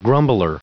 Prononciation du mot grumbler en anglais (fichier audio)
Prononciation du mot : grumbler